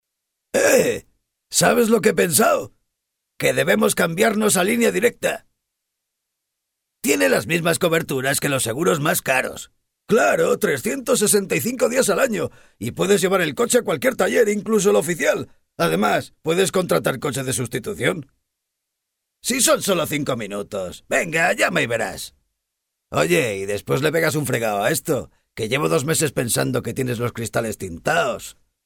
Para la realización de este promocional de televisión  nos pidieron las voces de varios muñecos, pero el cliente no estaba seguro de si quería una voz de mujer ,